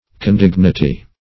Condignity \Con*dig"ni*ty\, n. [Cf. F. condignit['e].]